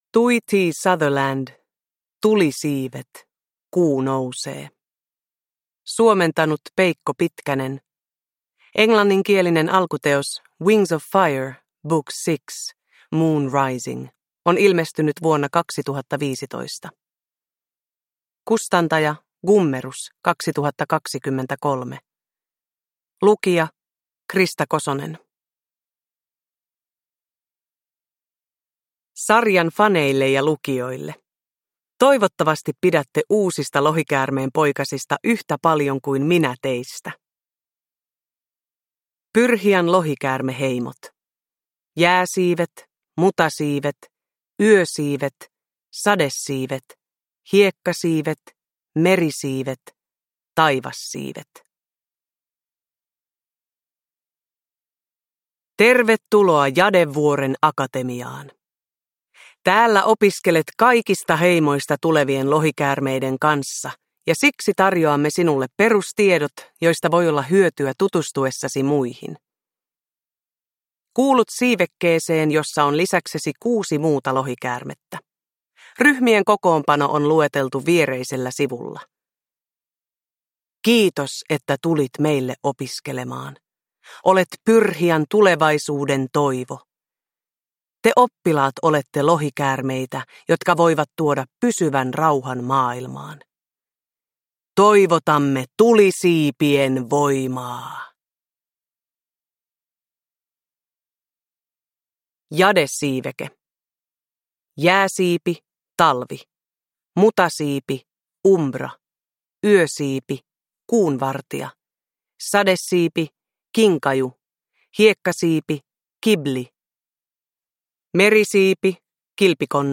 Kuu nousee – Ljudbok
Uppläsare: Krista Kosonen